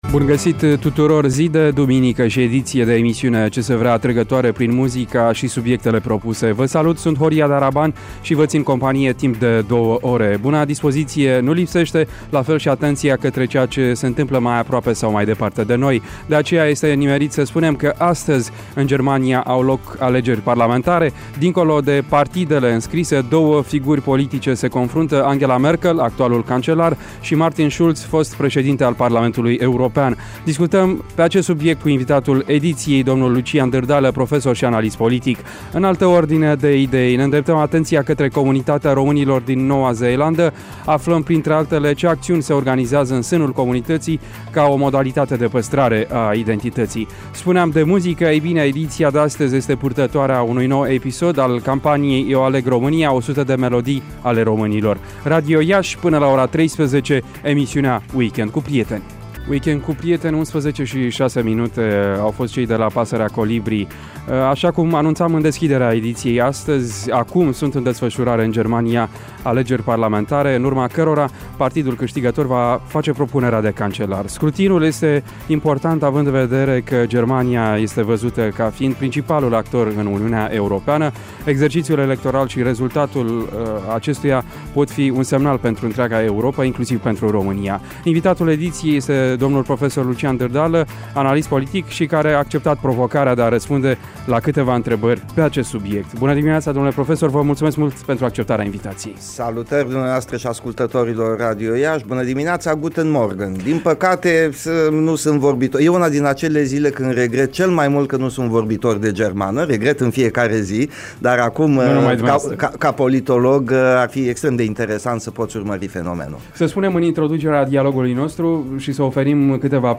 Un dialog